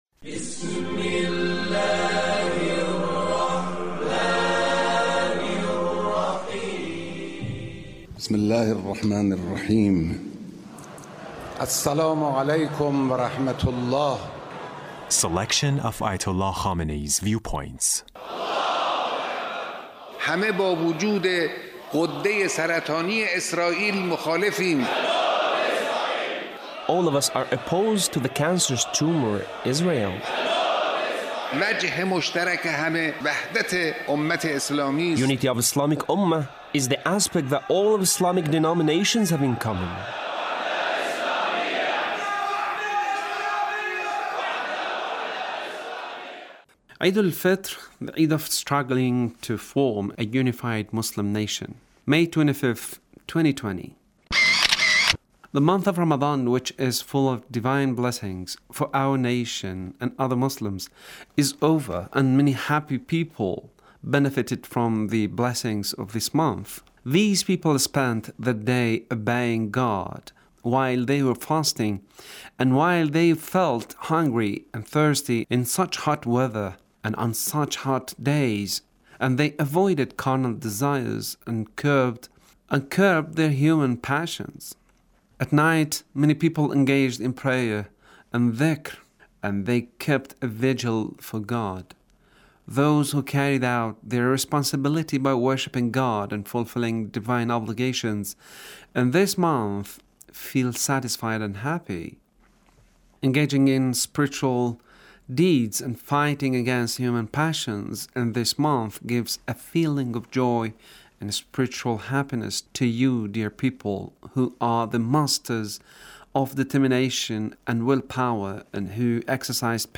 Leader's Speech on Eid al Fitr